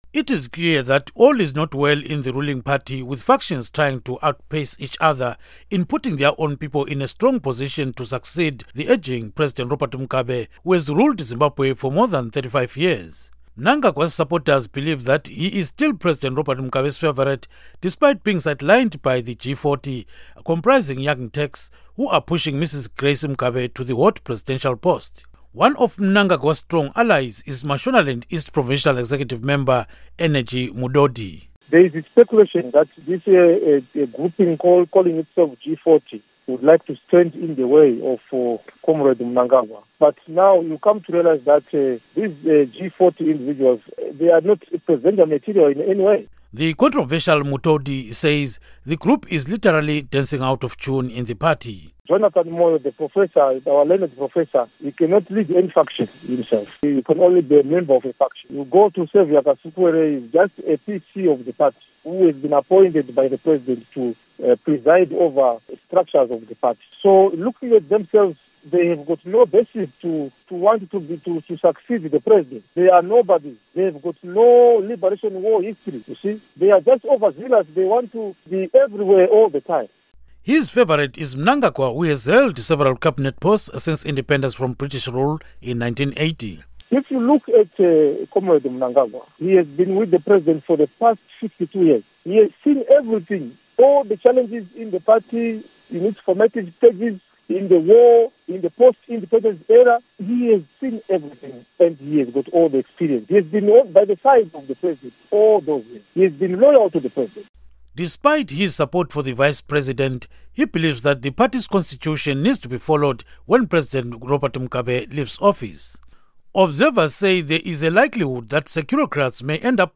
Report on Mugabe Succession